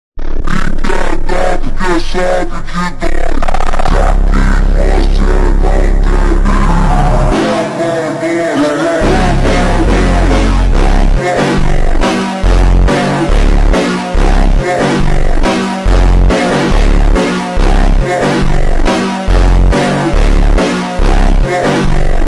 ещё один проект BMW m5 sound effects free download